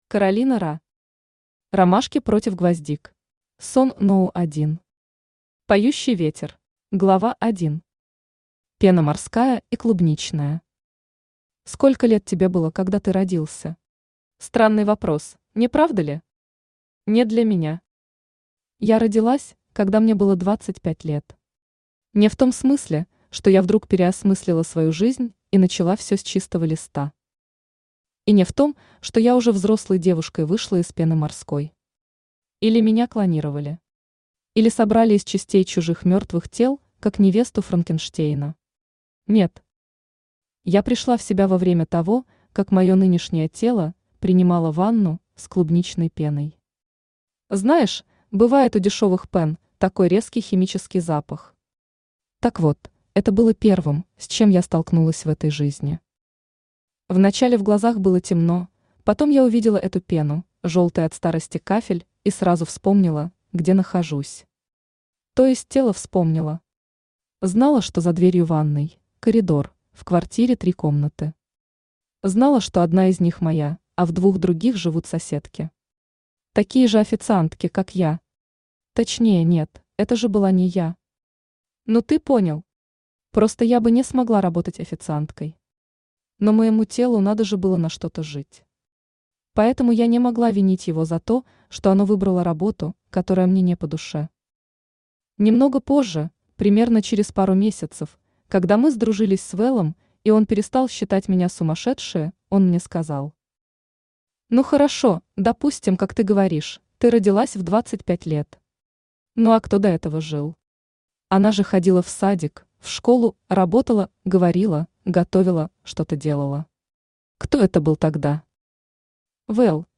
Аудиокнига Ромашки против гвоздик | Библиотека аудиокниг
Aудиокнига Ромашки против гвоздик Автор Каролина Ра Читает аудиокнигу Авточтец ЛитРес.